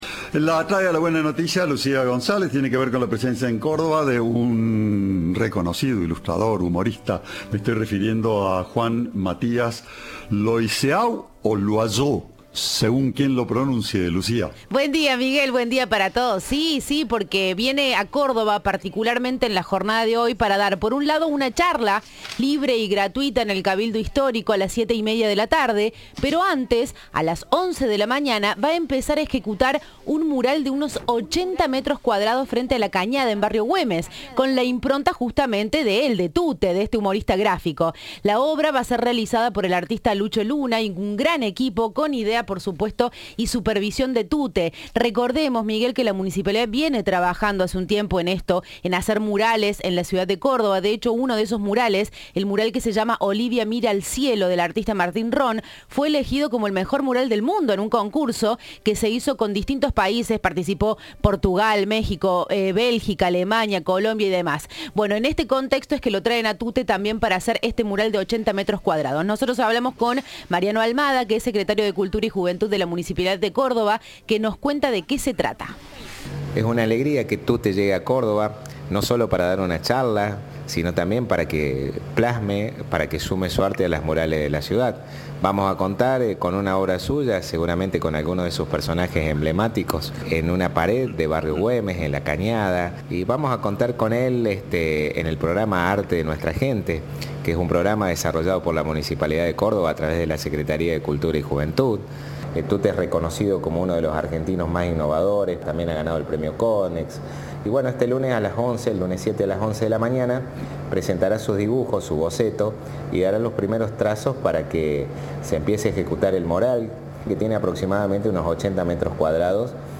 Mariano Almada, secretario de Juventud de Córdoba:
Informe